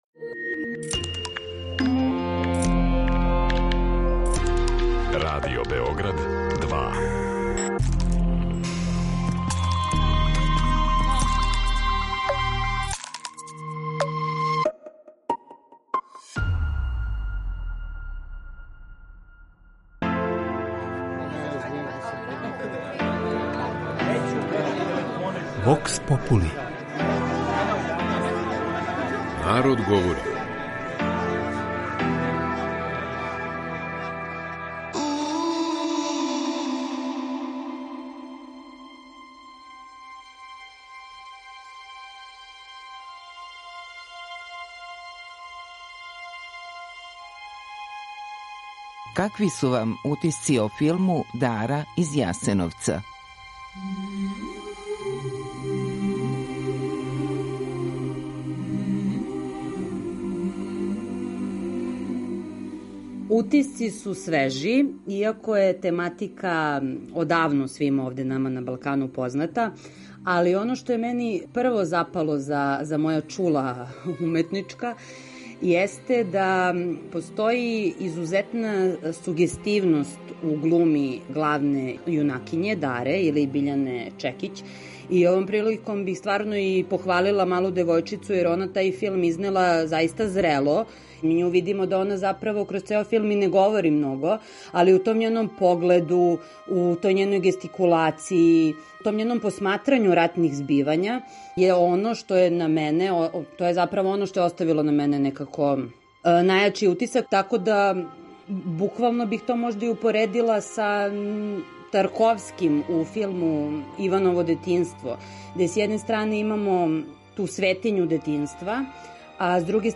У данашњој емисији замолили смо наше суграђане да пред микрофоном Радио Београда 2 поделе своје утиске о филму „Дара из Јасеновца", премијерно емитованом у суботу 20. фебруара на РТС-у.